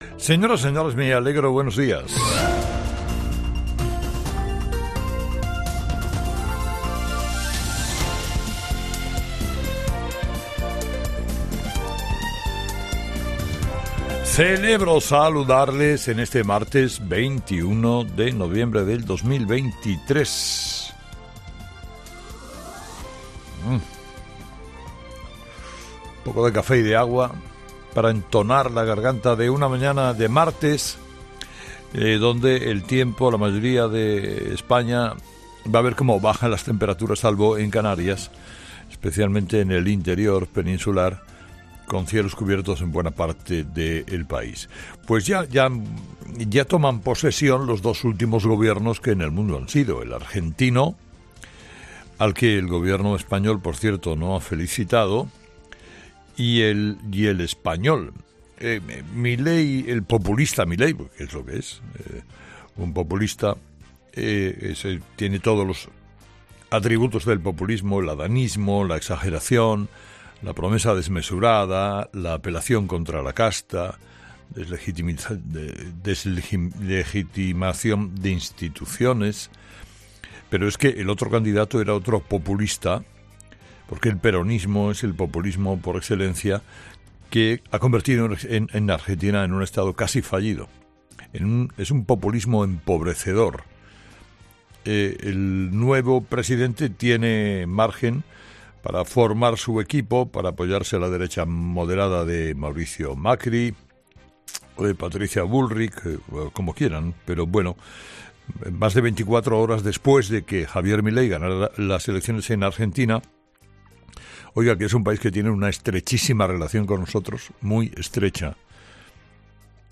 Carlos Herrera, director y presentador de 'Herrera en COPE', comienza el programa de este martes analizando las principales claves de la jornada que pasan, entre otras cosas, los secretos de los 22 ministros del Gobierno de Pedro Sánchez.